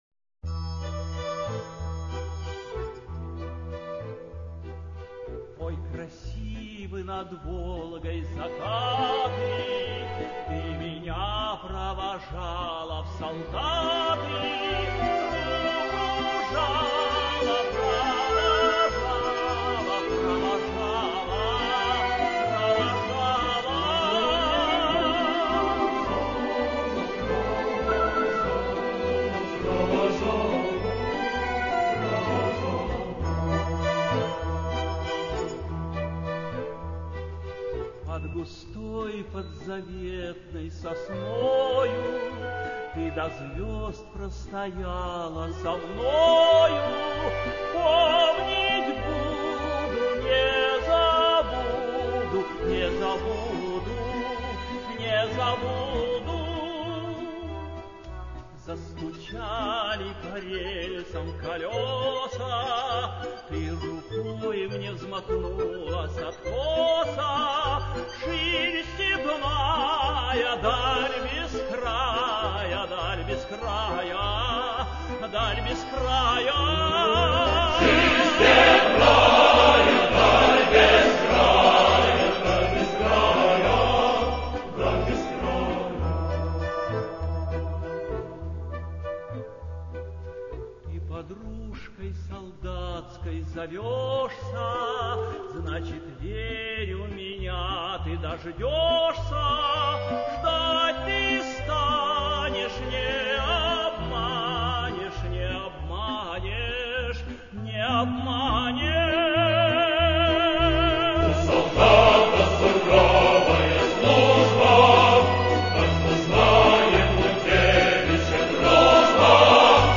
Описание: Известнейшая послевоенная песня